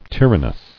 [tyr·an·nous]